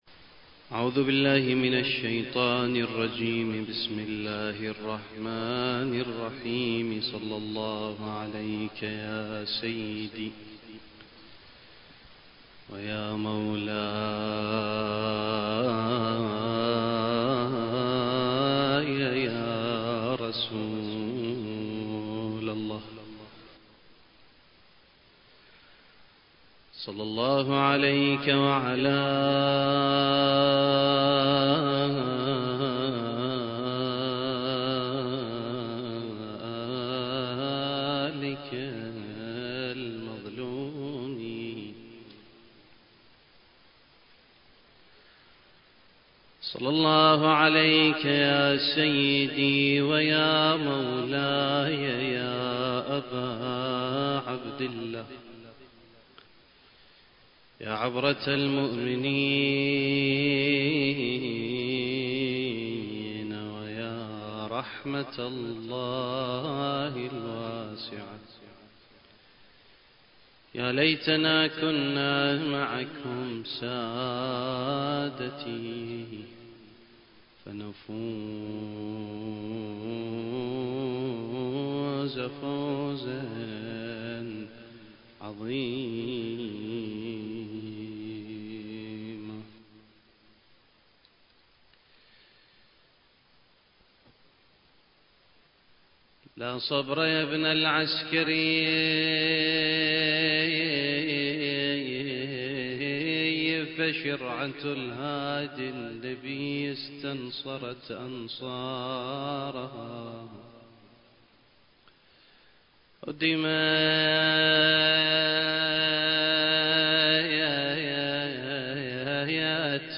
المكان: الامارات/ الشارقة/ حسينية الإمام الحسن (عليه السلام) التاريخ: 1444 للهجرة